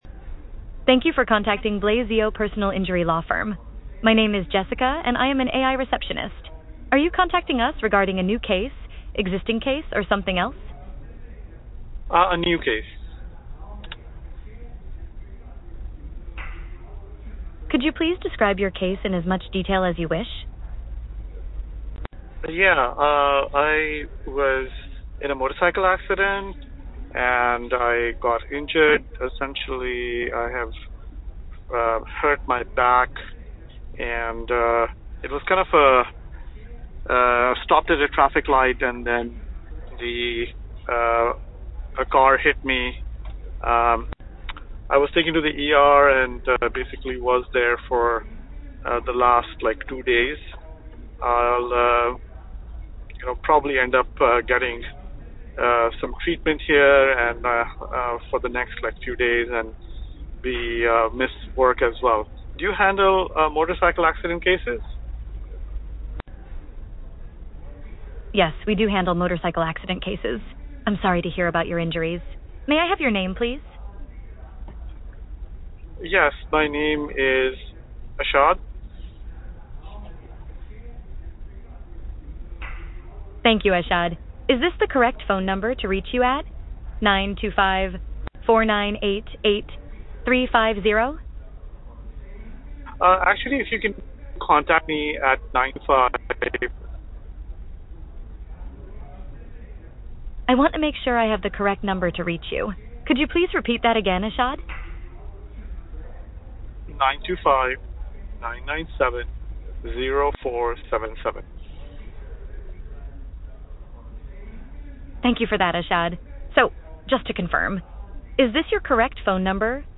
A personal injury law firm receives a call from a prospective client.
This shows empathy — a critical factor in legal and medical industries where tone matters as much as efficiency.
With one click, the caller is routed to a live staff member.
voice-ai-demo-recording.mp3